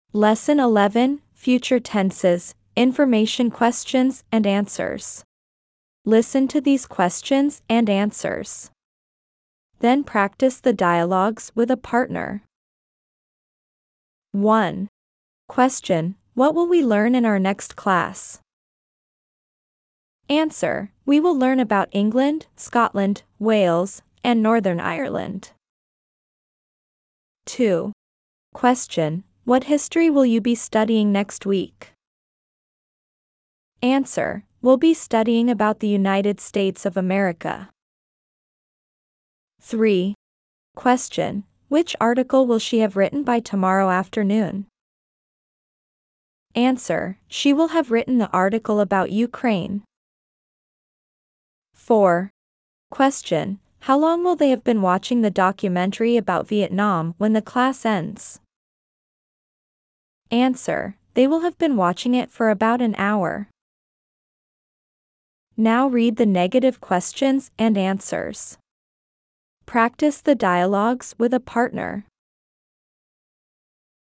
As you progress through the exercises and listen to the example statements and dialogs, you’ll not only strengthen your grasp on key English tenses and sentence structures but also enrich your vocabulary with the English names of countries from around the globe.